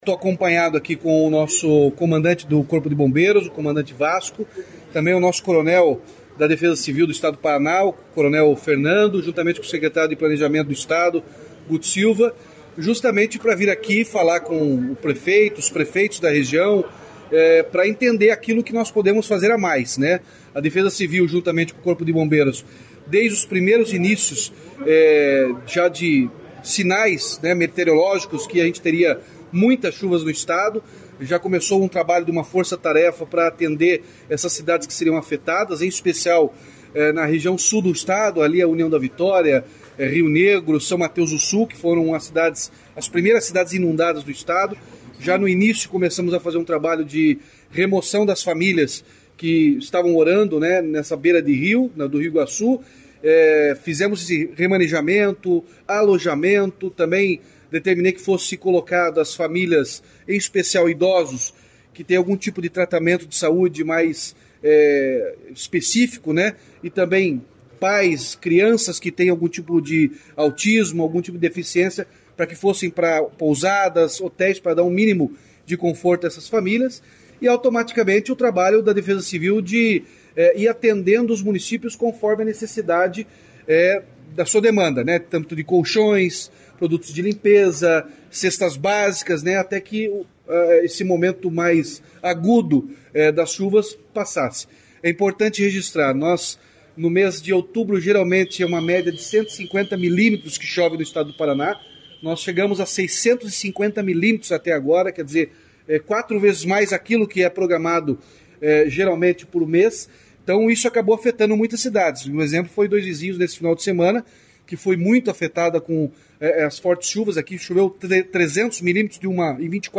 Sonora do governador Ratinho Junior sobre as enchentes em Dois Vizinhos e em todo o Paraná